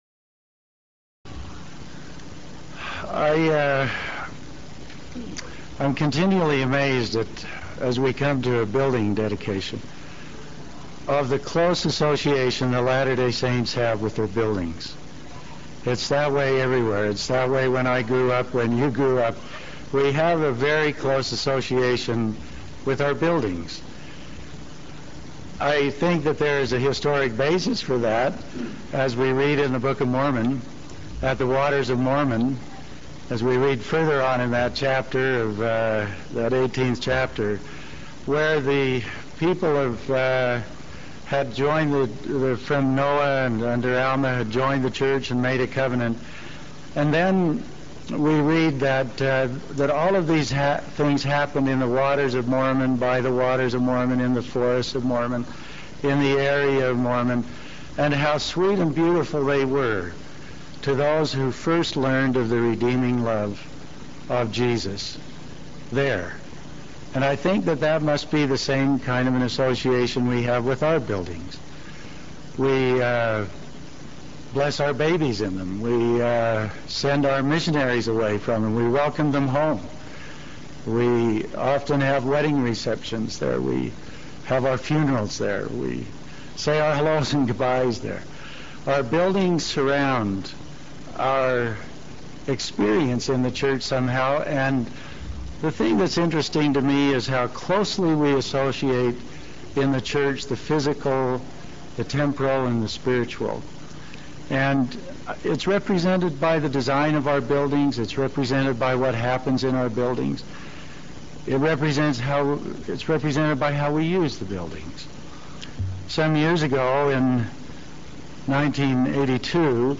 Elder Dennis B. Neuenschwander's Talk
attachment-dedication-dennis-b-neuenschwanders-talk